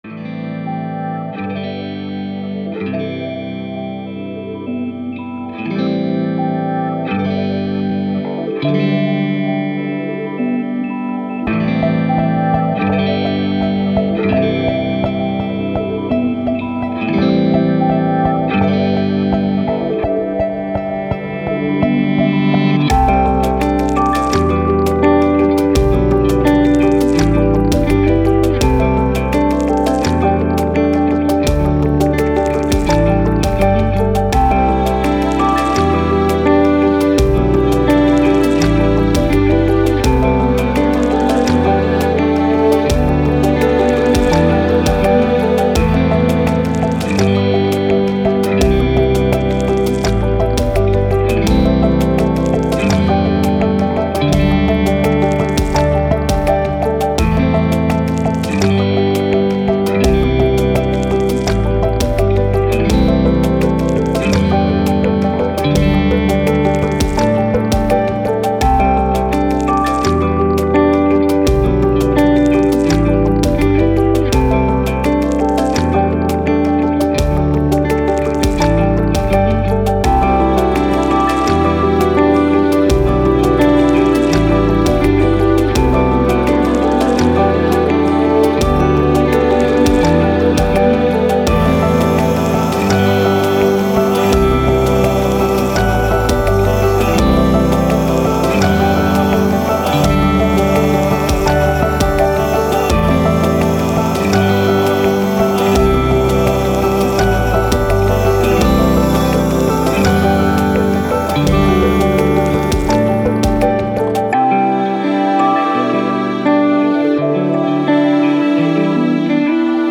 Downtempo, Chilled, Emotive, Ambient